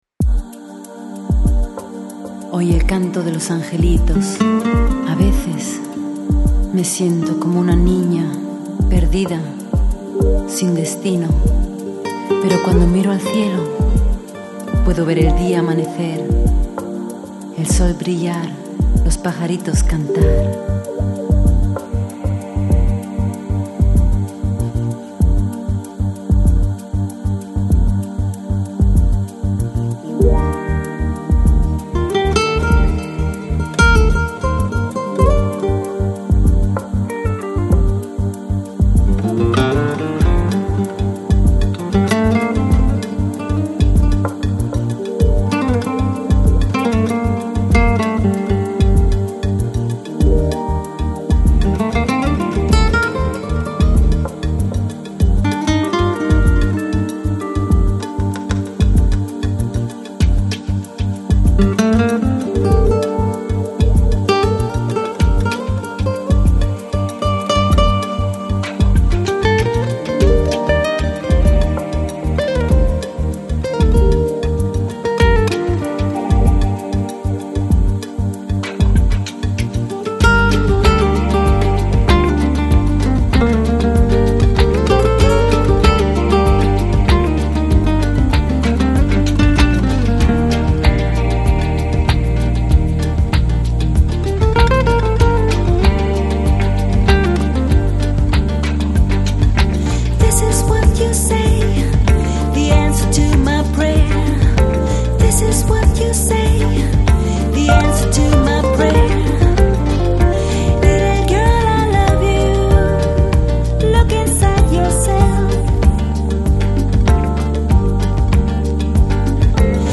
Lounge, Chill Out, Downtempo, Electronic